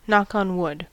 Uttal
US: IPA : /ˈnɑk ɑn ˈwʊd/